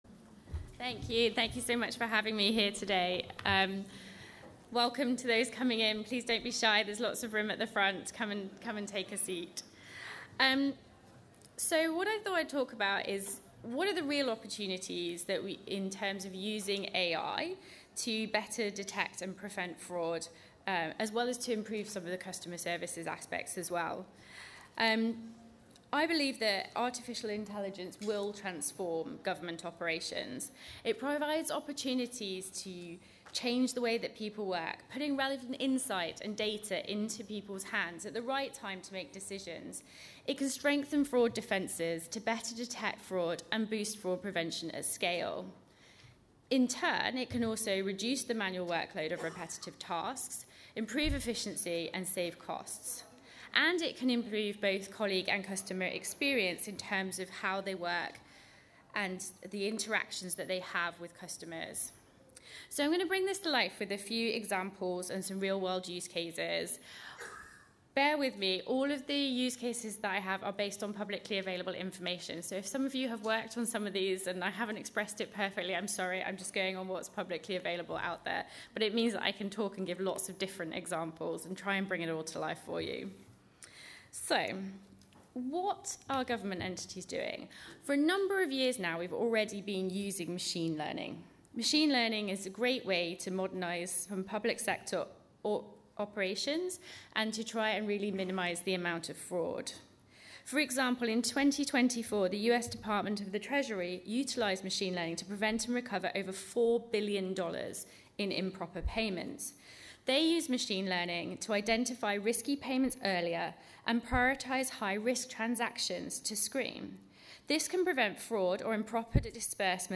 Click the sound wave to hear her presentation in full: